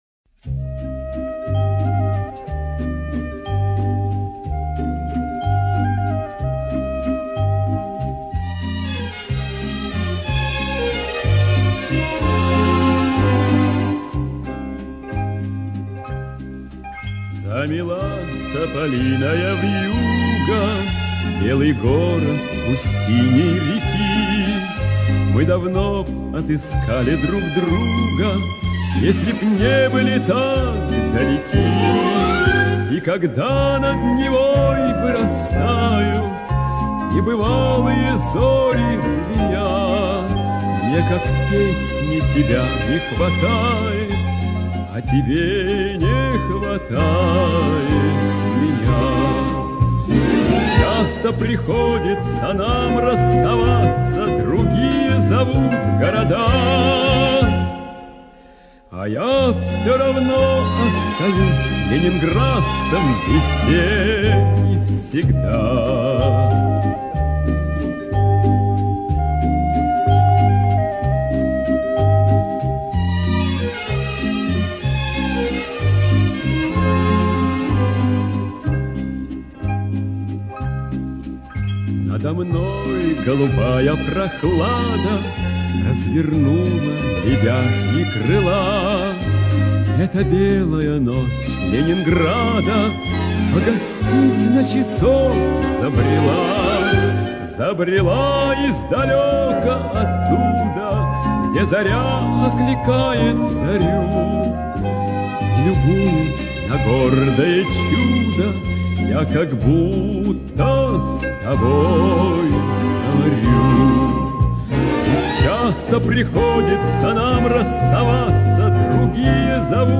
советский и российский певец (баритон).